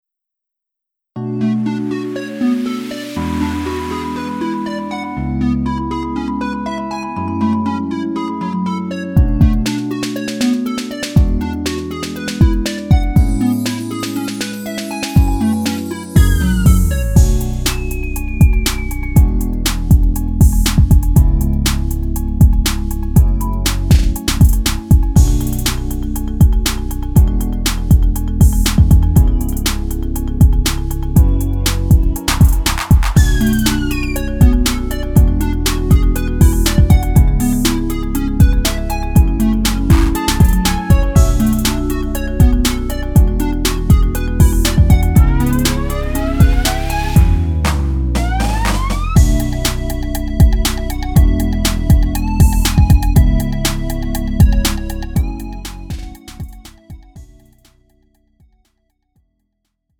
음정 원키 3:25
장르 가요 구분 Lite MR